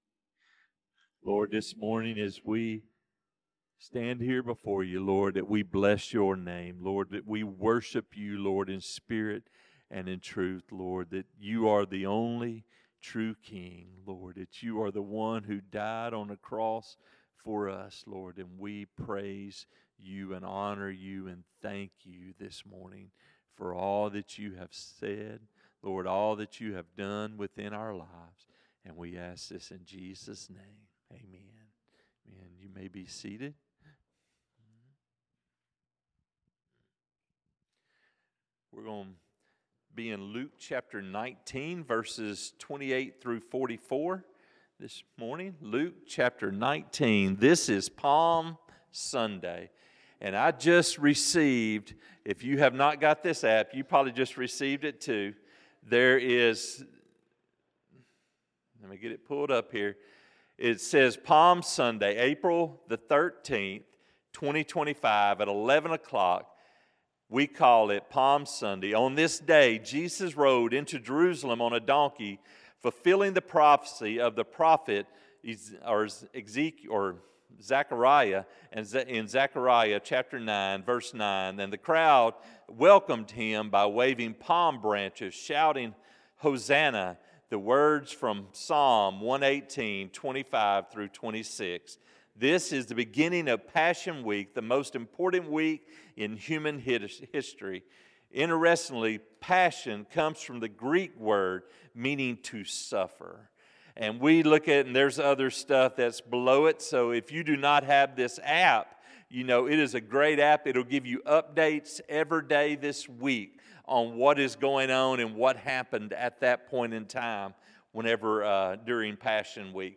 Sermons | First Southern Baptist Church Bearden
Sunday Morning 04-13-25 "Palm Sunday"